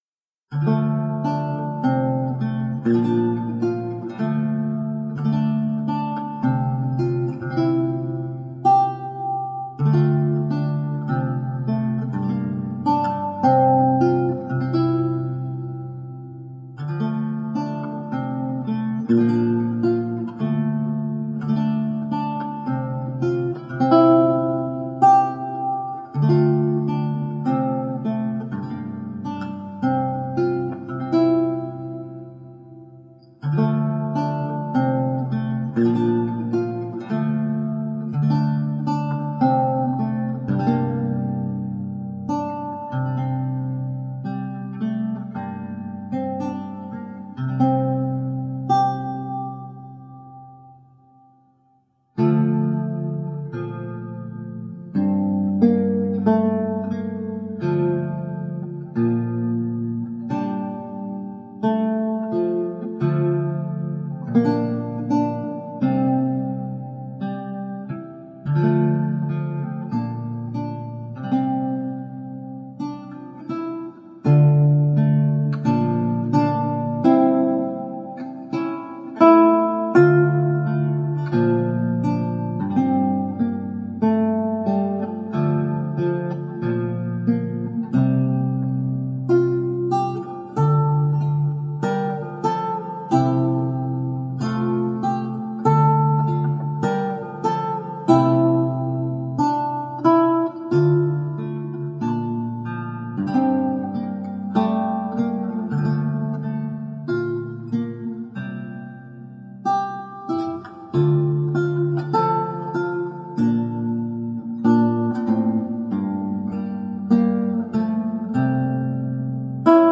Original and evocative lute music.